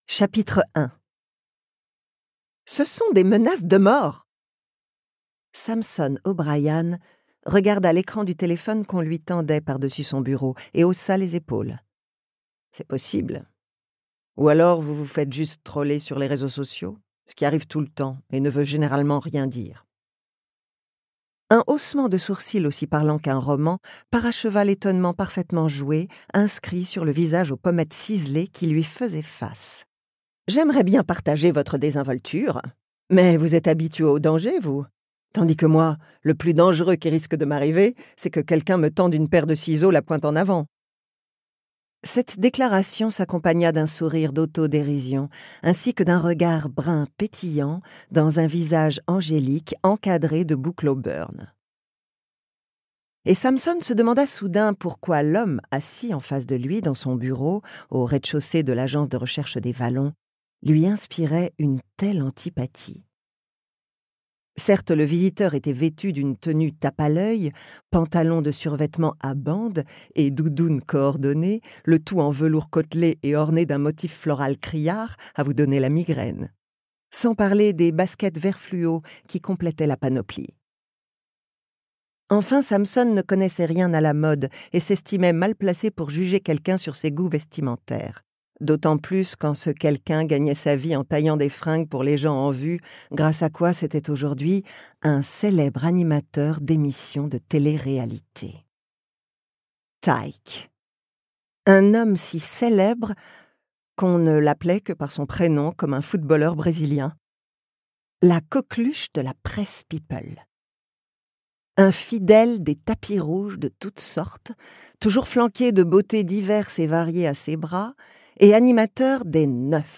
la voix pétillante et captivante